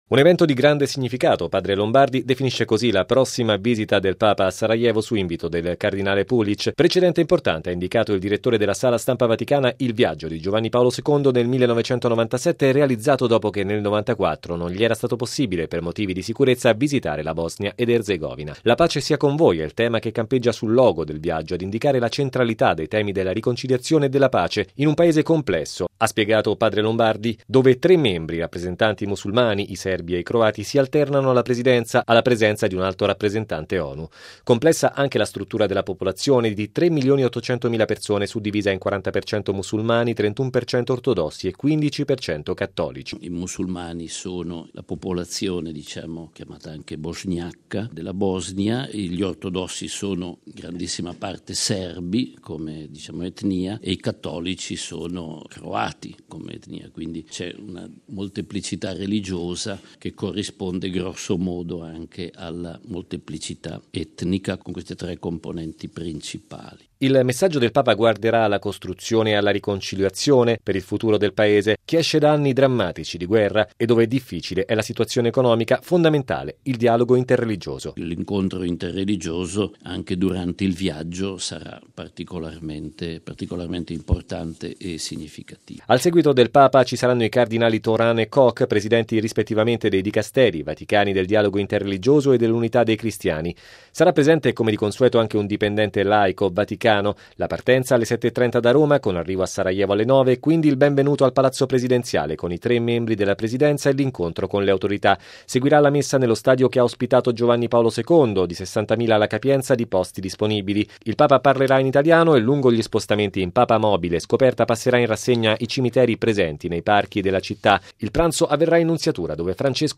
Logo 50 Radiogiornale Radio Vaticana